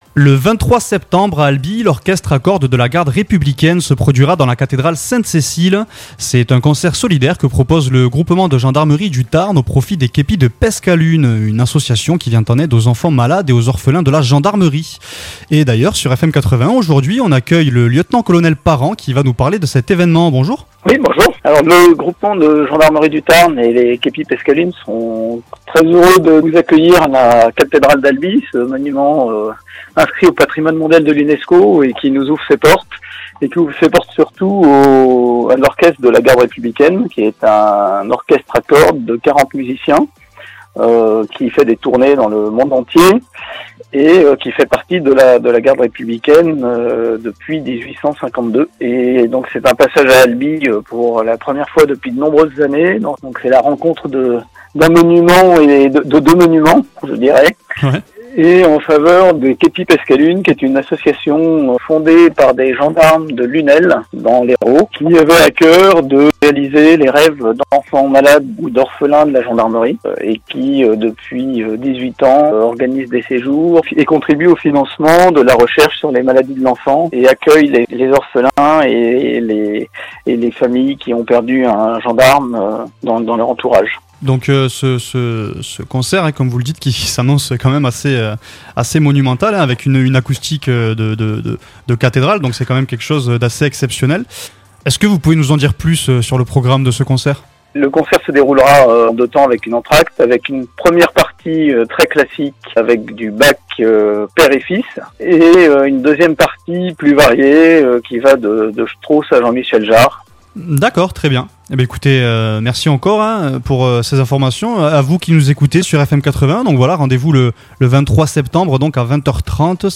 Concert dans la cathédrale d'Albi
Orchestre à cordes de la garde républicaine